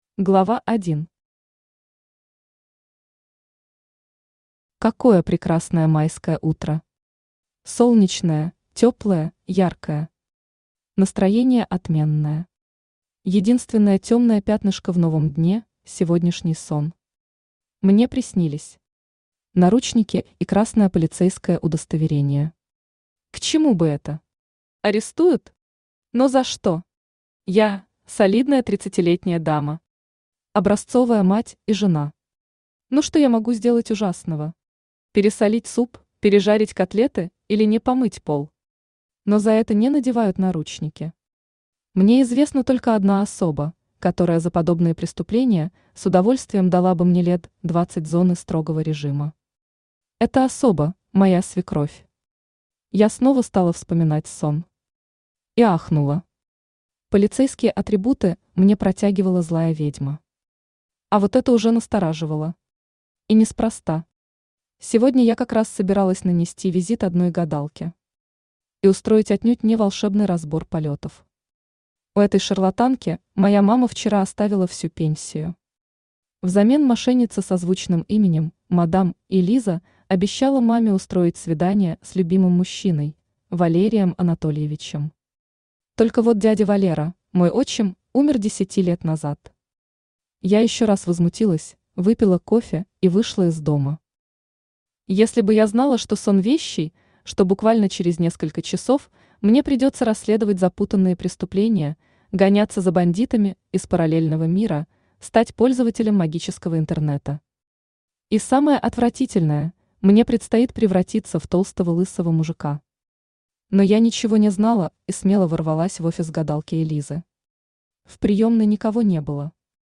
Аудиокнига Попаданка по ошибке. Страшно-смешная магия обманутой жены | Библиотека аудиокниг
Aудиокнига Попаданка по ошибке. Страшно-смешная магия обманутой жены Автор Лилия Тимофеева Читает аудиокнигу Авточтец ЛитРес.